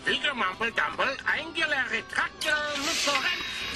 absolute gibberish. Think of the Muppets’ Swedish Chef with every single, solitary shred of charisma sapped from him.
notswedishchef.mp3